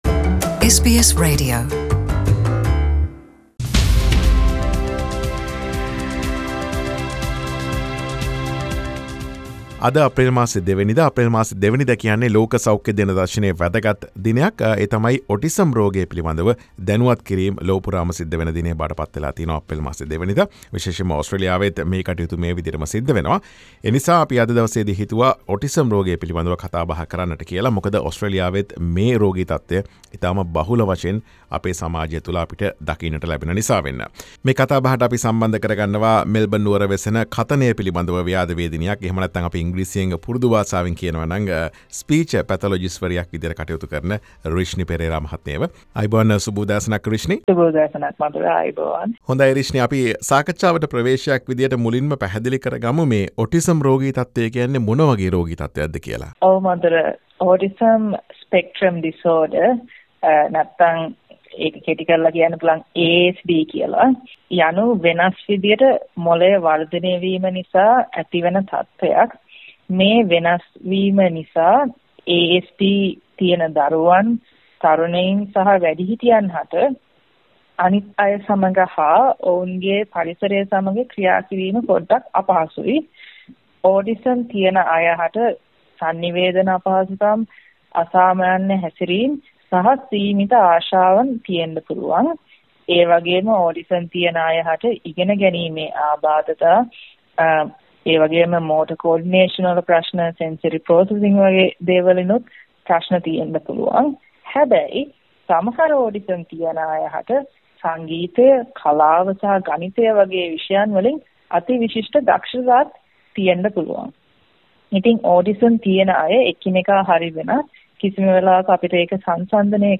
SBS සිංහල වැඩසටහන සිදු කළ සාකච්ඡාව.